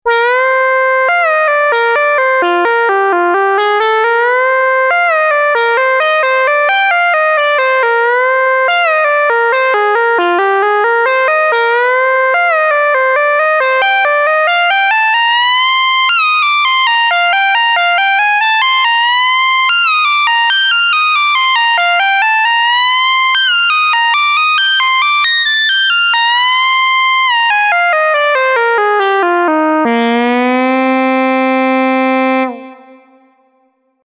Everything was played right on the AstroLab, and there was no extra sound processing done.
We’ve used Soft Reso Lead to showcase the pitch bend wheel and aftertouch. The latter controls vibrato amount and adds a nice layer of expression to the melodic line.